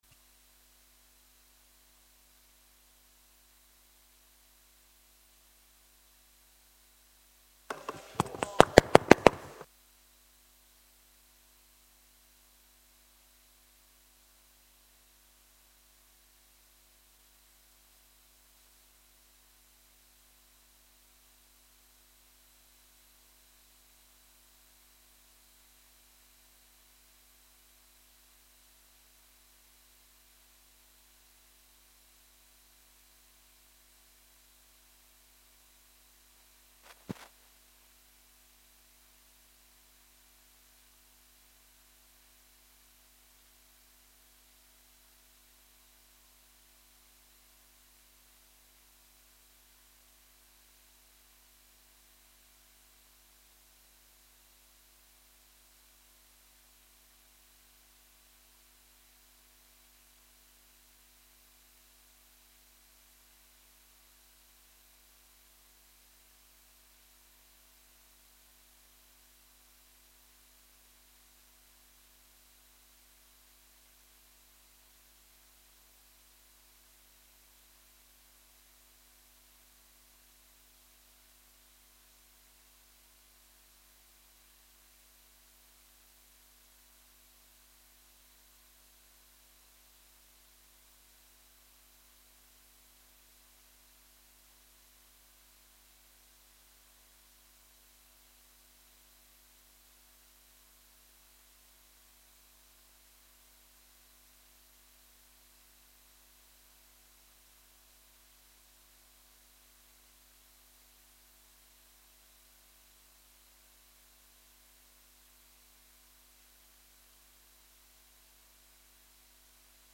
Registrazioni audio delle sedute del consiglio comunale dal 2025